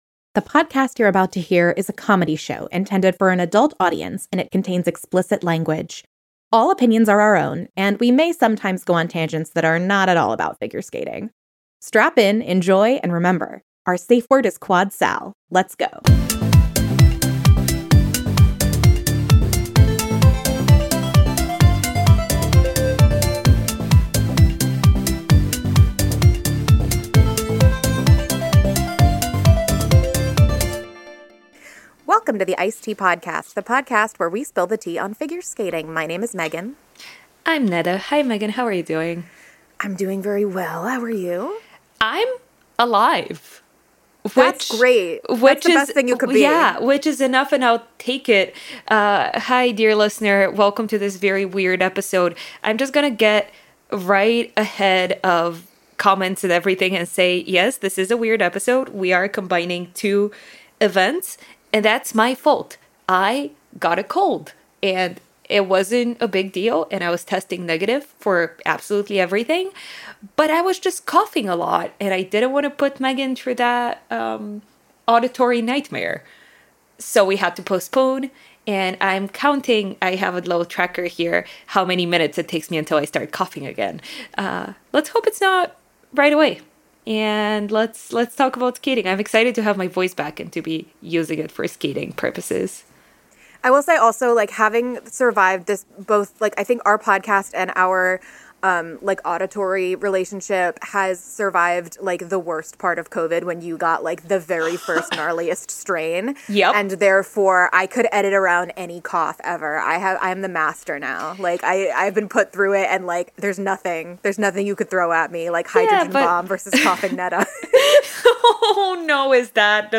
audio sounds a little crazy for parts of this